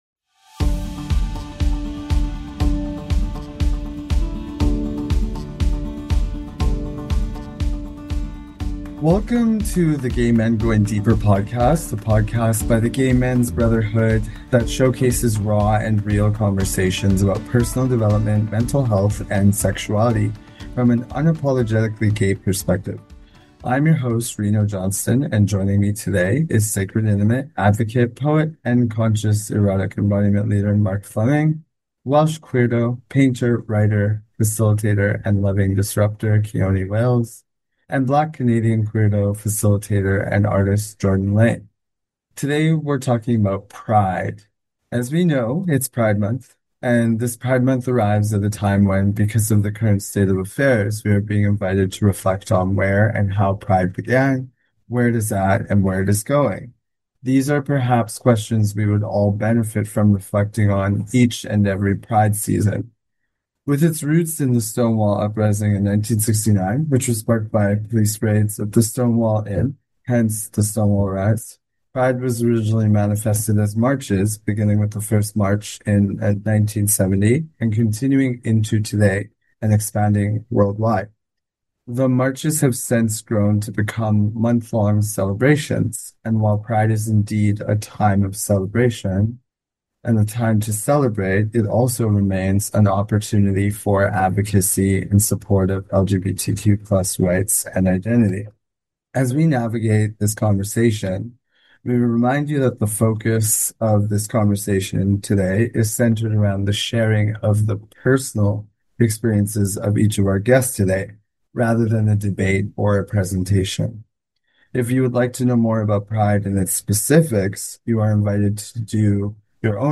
Pride Panel Discussion